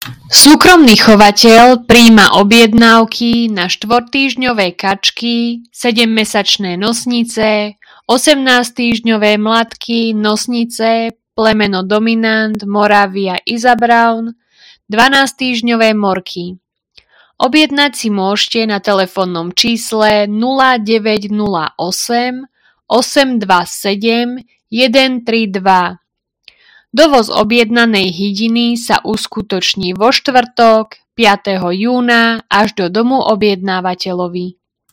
Hlásenie obecného rozhlasu – Predaj hydiny